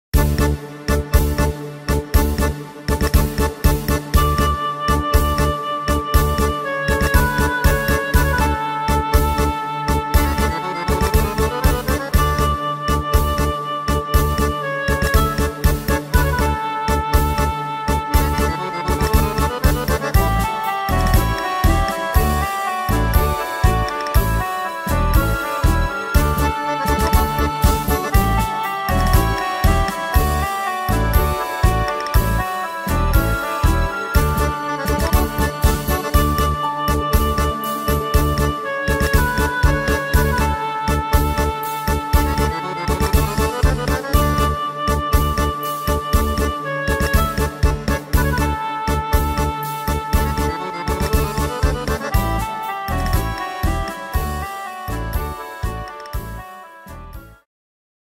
Tempo: 120 / Tonart: D-Dur